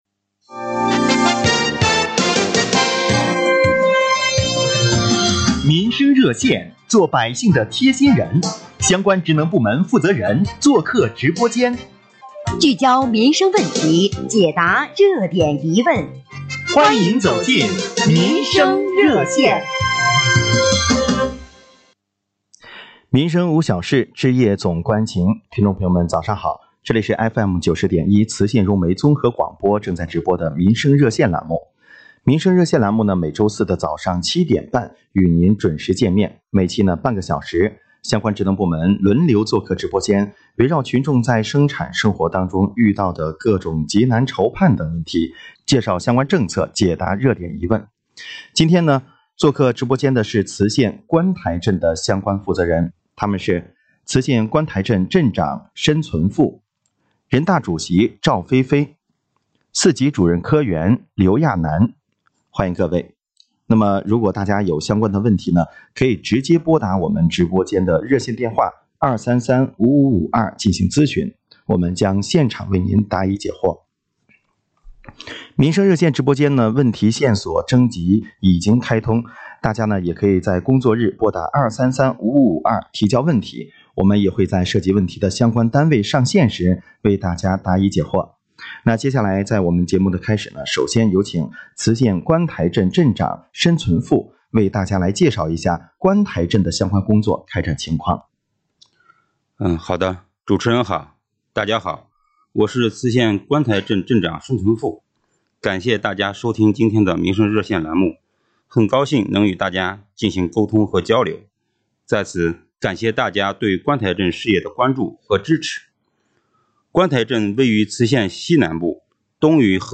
节目进行过程中，就听众电话咨询的“城乡居民养老保险年初忘记缴费，现在可以补缴吗？准生证网上可以办理吗？怎么申请办理低保？”等问题，观台镇相关负责同志一一给予解答。